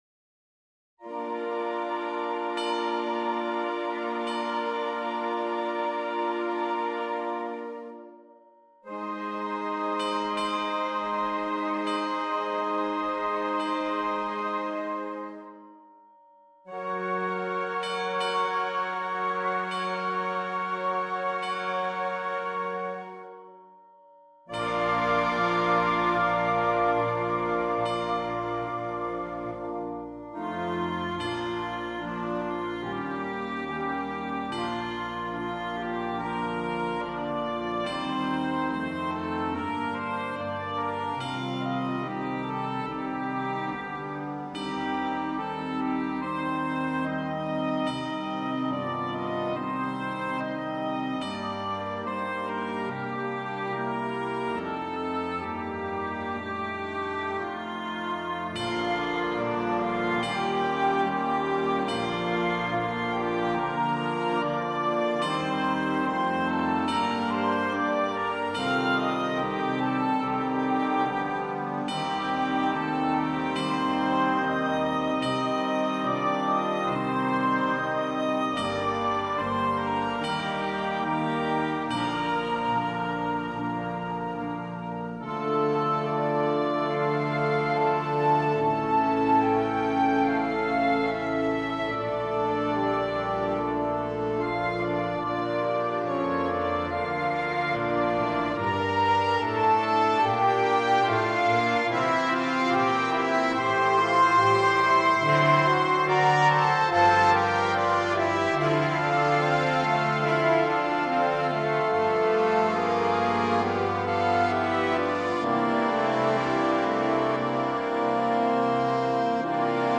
Per banda
Trittico sinfonico